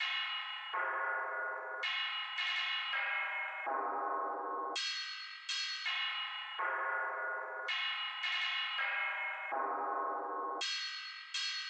寺庙崇拜的钟声 164
标签： 164 bpm Trap Loops Bells Loops 1.97 MB wav Key : E Cubase
声道立体声